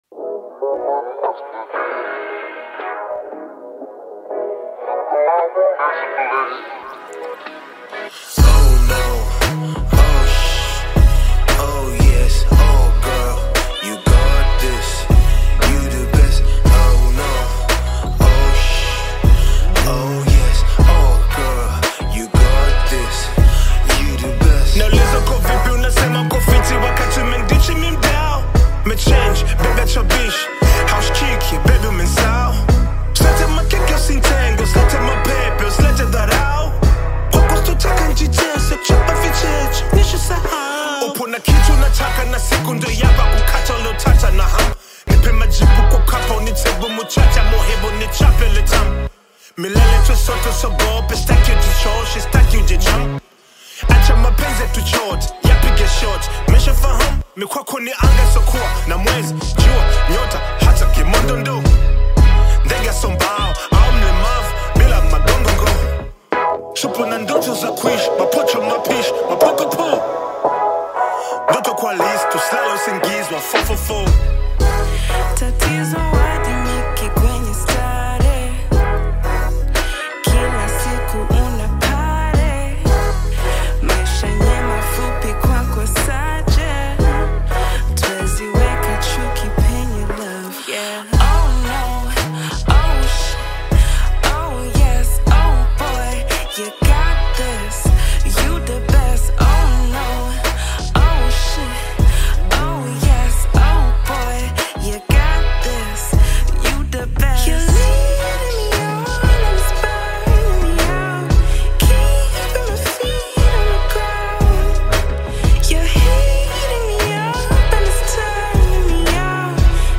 rap
soulful songstress
powerful and emotionally charged
rich and soulful vocals